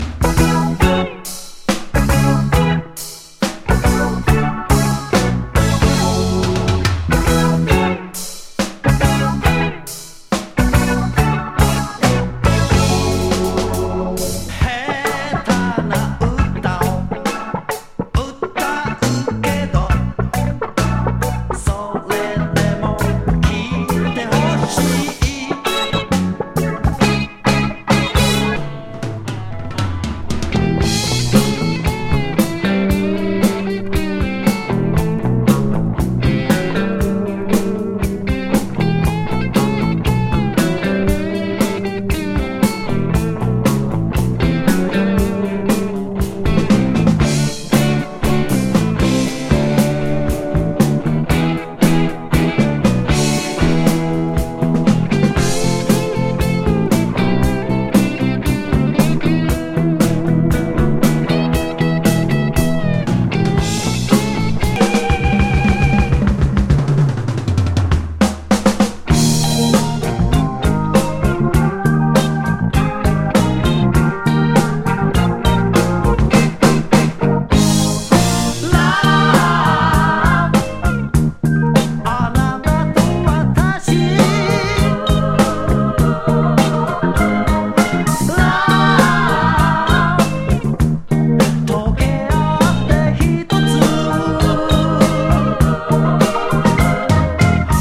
レゲー
サザン・ソウル・グルーヴィン